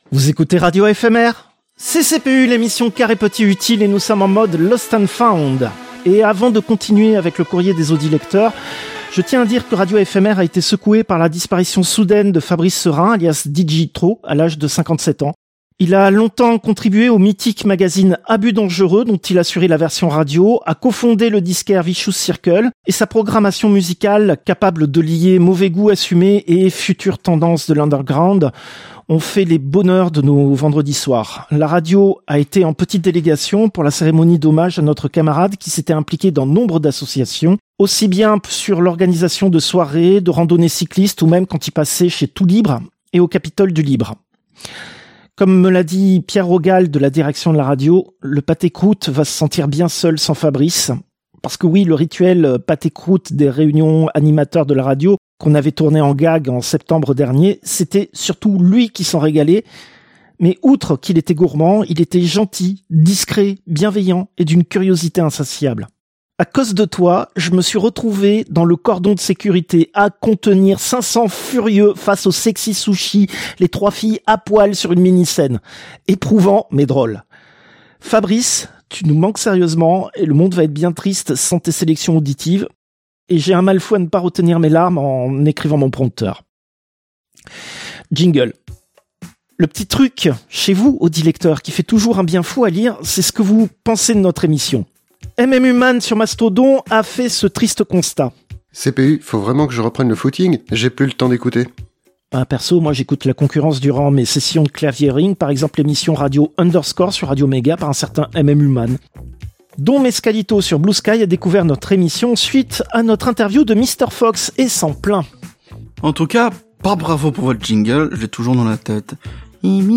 Extrait de l'émission CPU release Ex0241 : lost + found (avril 2026).